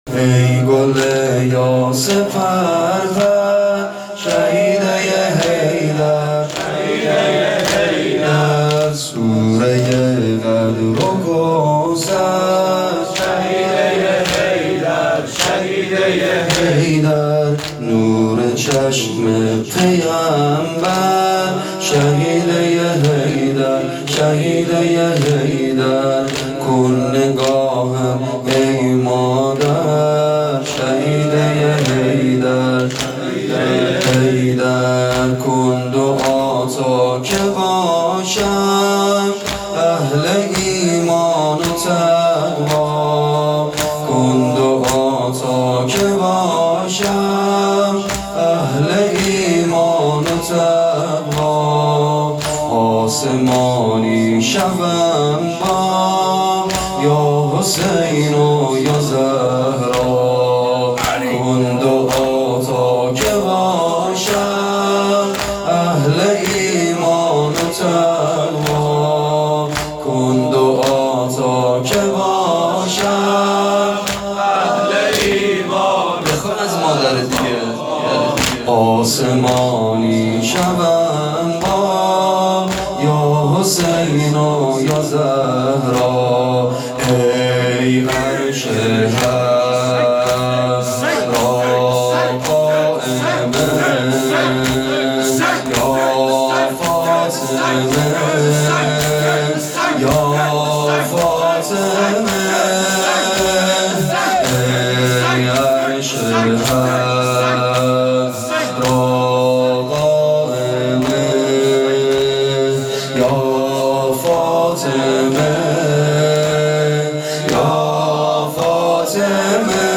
زمینه شام شهادت حضرت زهرا(س)_فاطمیه اول۹۷